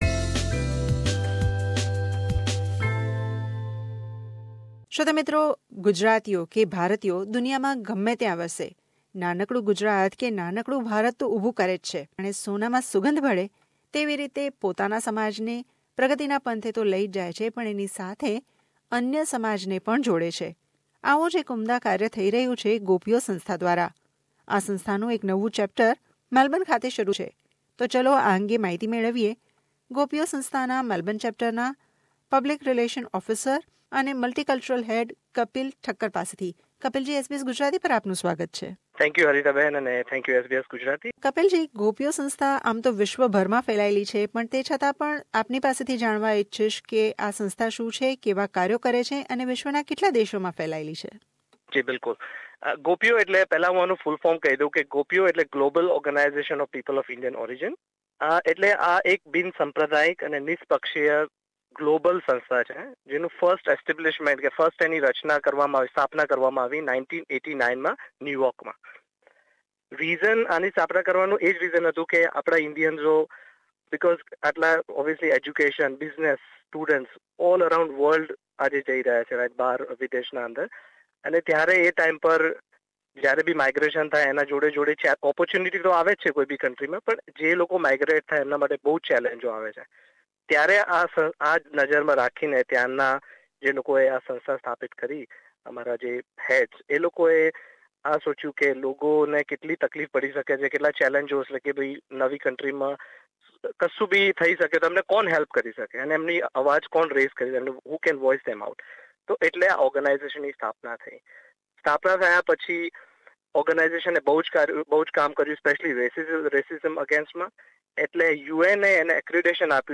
SBS સાથે વાતચીત કરી હતી.